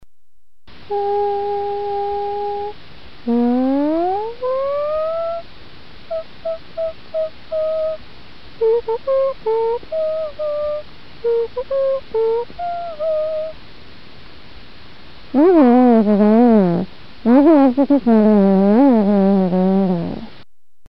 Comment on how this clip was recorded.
This voice data does not capture the actual words spoken. Instead, it captures the wearer’s pitch, volume, and amount of vocalizations. LISTEN: adult voice sample as picked up by the device, for example only